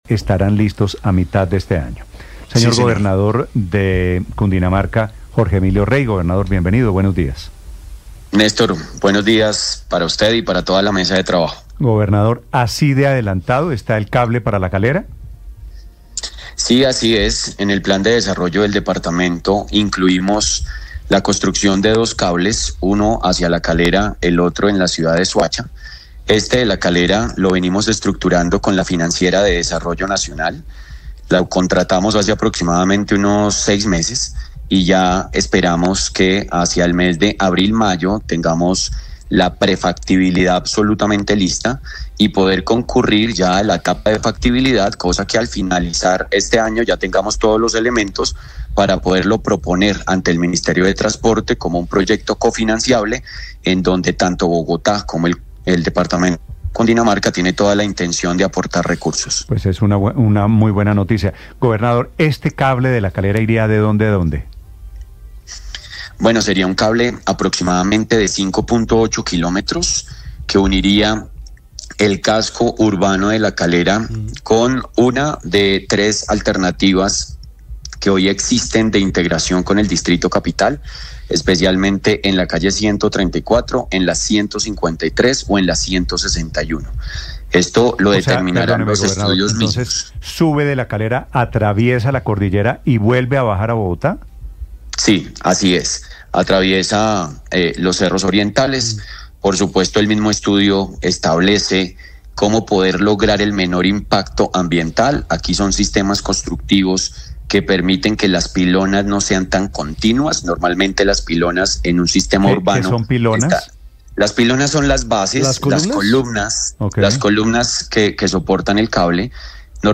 Los cables aéreos a Soacha y a La Calera, que constituirían una alternativa de movilidad entre esos dos municipios y Bogotá, para miles de personas, quedarían contratados en la actual administración del gobernador Jorge Emilio Rey, quien habló sobre el avance de ambos proyectos en Blu Radio.
Entrevista+Blu+radio,+Gobernador-+(1).mp3